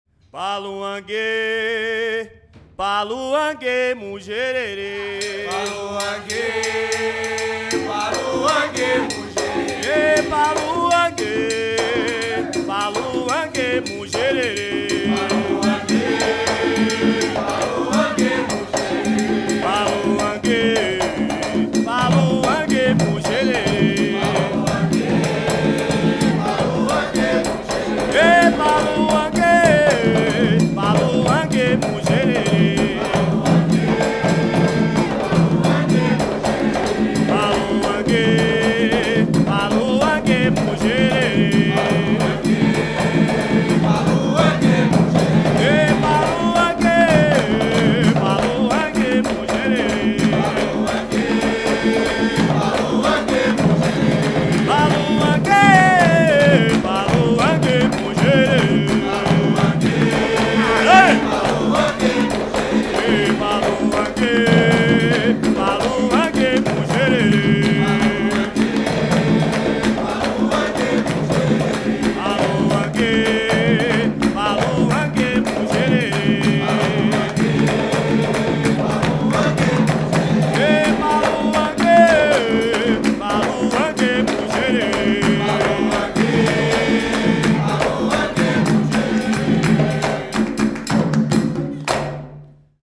GêneroCandomblé Angola
Nos toques do candomblé Angola, como o cabula, barravento, congo de ouro, muzenza e ijexá, ouve-se com clareza as matrizes rítmicas, formais e melódicas de nossa música urbana.
e acompanhadas pelos inkisses ngoma, os tambores rituais.